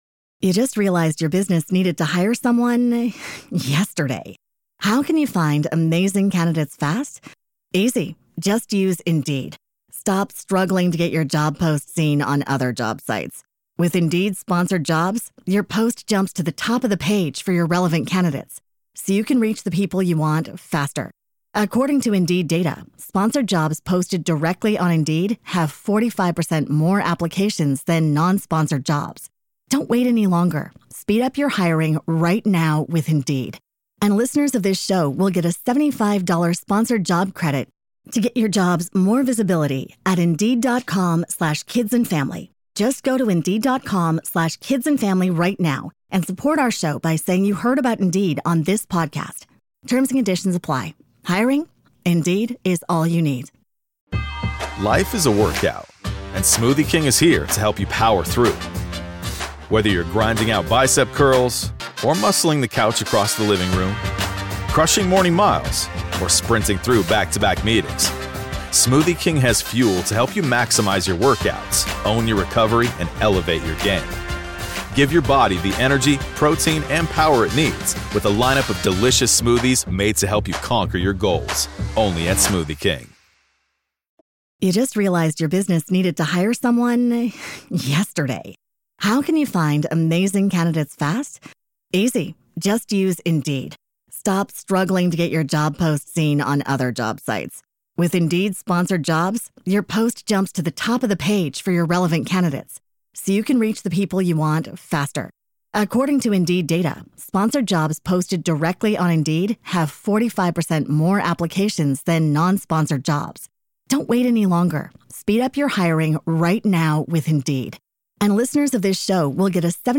The conversation highlighted practical lifestyle tips for maintaining a healthy balance.